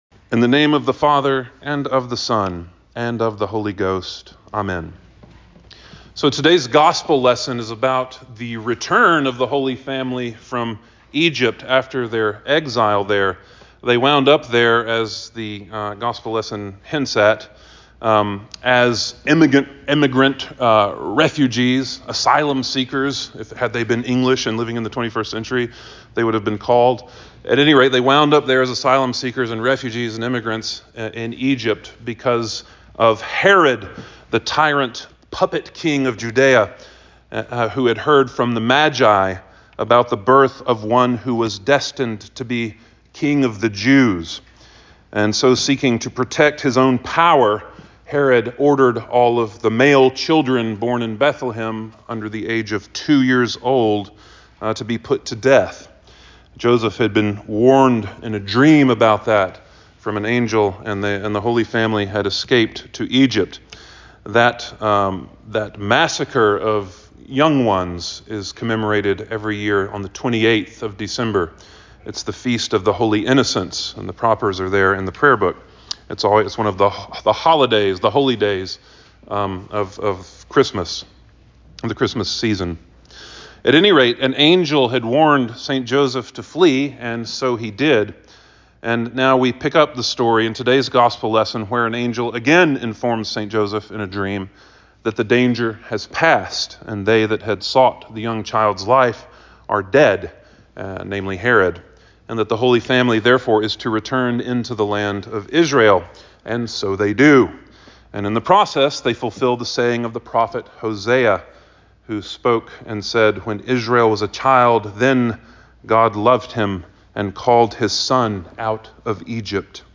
Sermon for the Second Sunday After Christmas 01.5.25